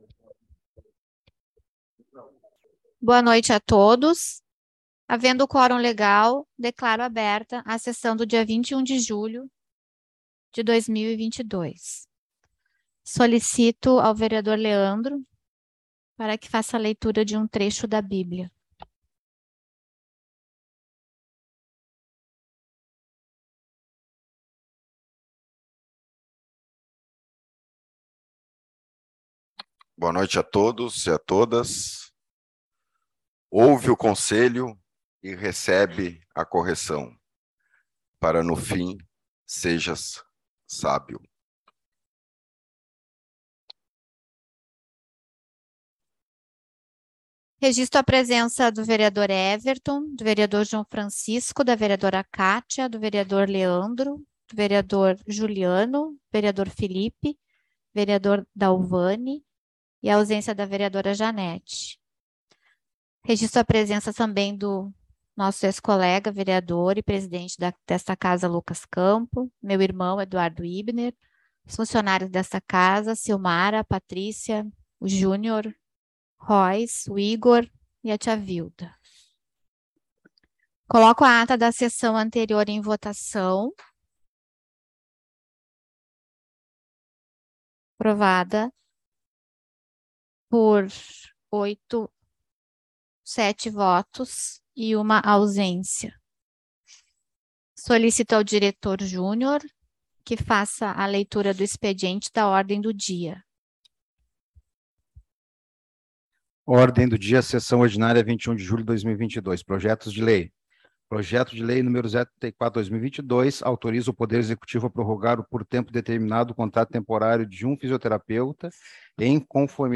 Sessão Ordinária 21.07.2022.mp3